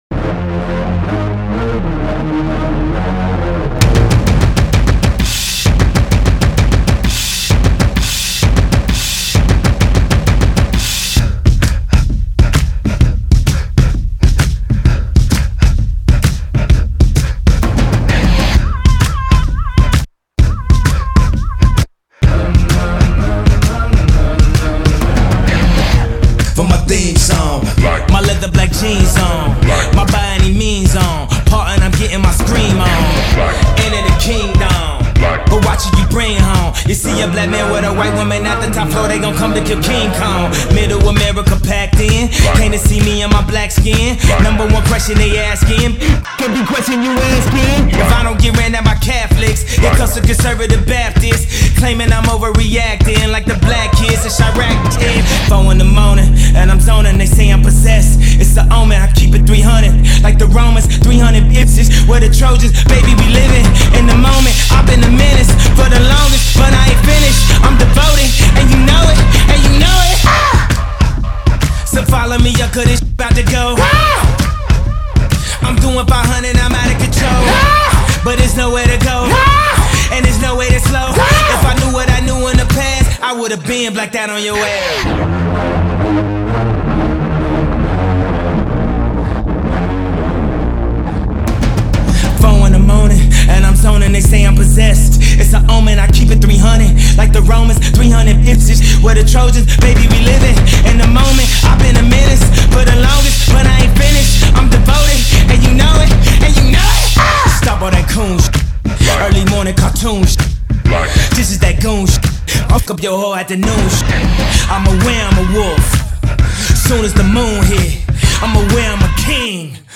you can listen to the radio/clean edit below.